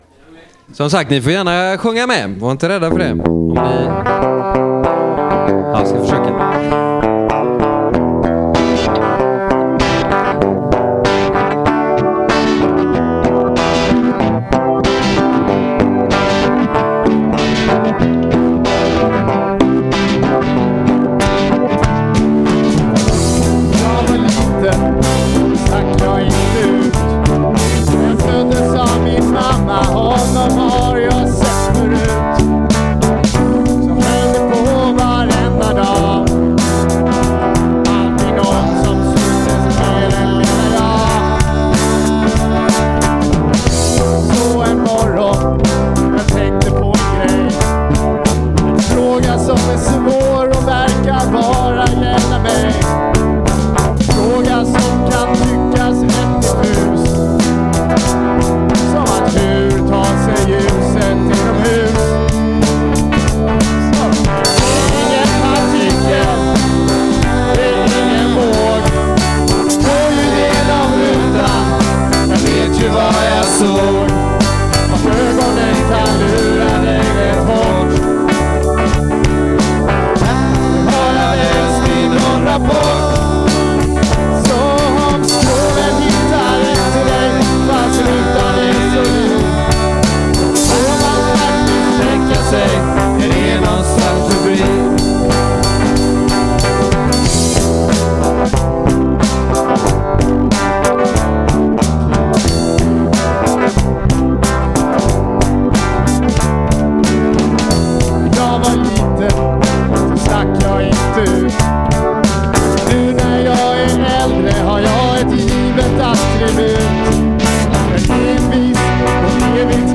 Video (m4v) -- 80 MB Musik (m4a) -- 5MB Live på Vetenskapsfestivalen, Chalmers, 20 april 2012.